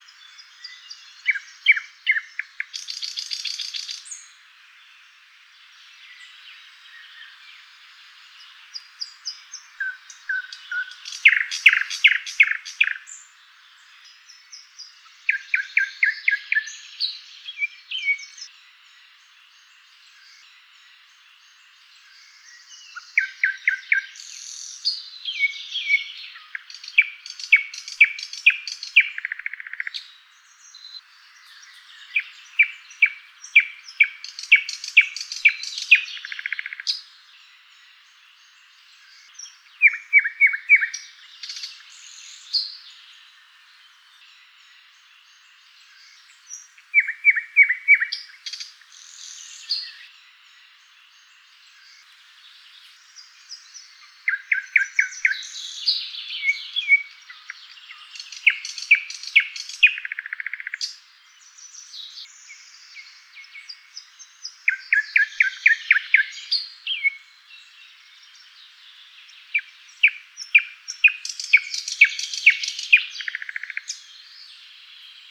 Privighetoarea de zăvoi – Luscinia luscinia
Privighetoarea-2-Luscinia-luscinia.mp3